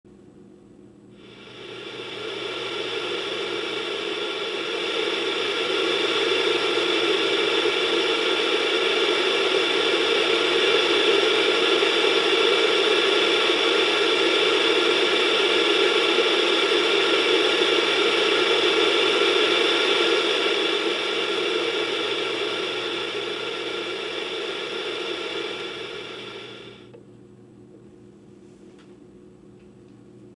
Download White Noise sound effect for free.
White Noise